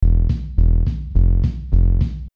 Попробовал покрутить так, чтоб не было разницы, но не вышло сделать волну идеально симметричной.
Звук различается при перевороте фазы, но зато сейчас не так сильно съедается низ.